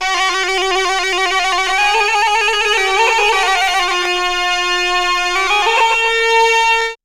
AFGANPIPE2-L.wav